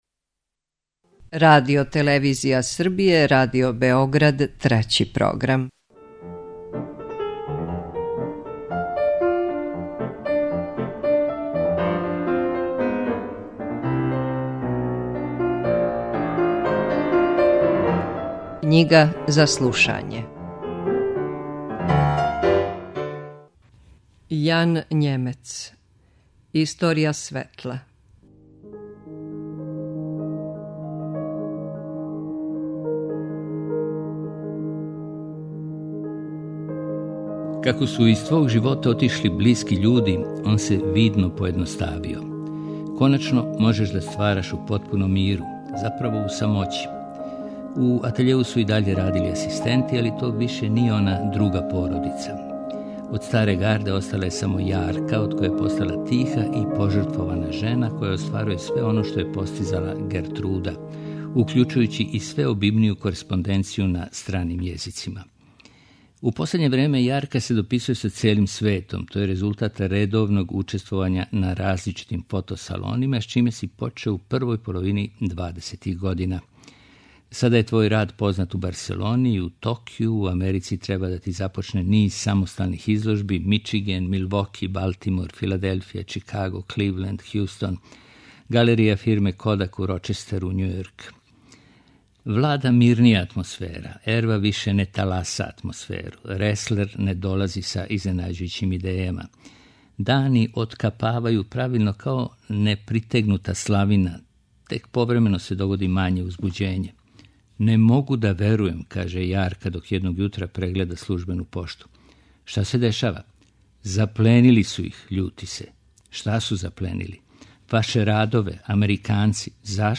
Knjiga za slušanje